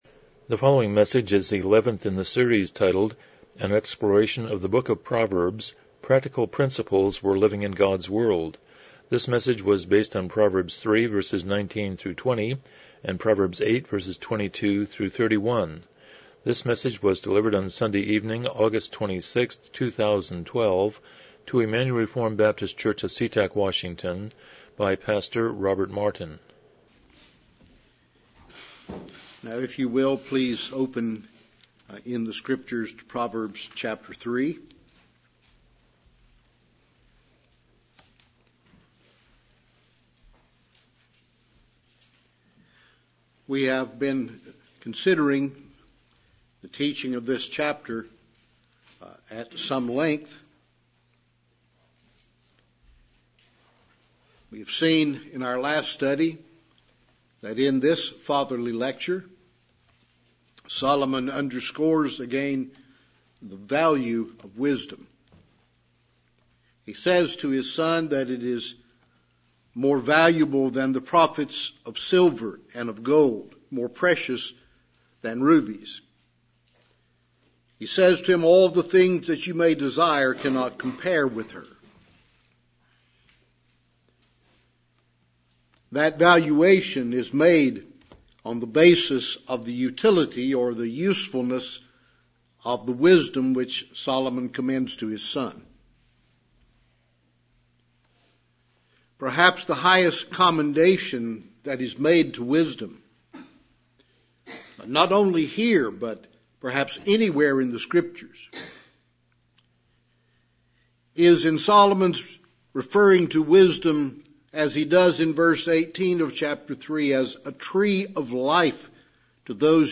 Passage: Proverbs 3:19-20, Proverbs 8:22-31 Service Type: Evening Worship